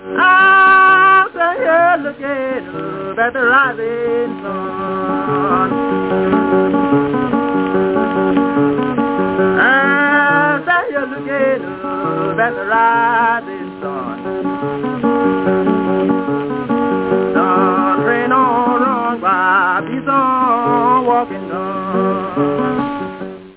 блюзовый вокалист и гитарист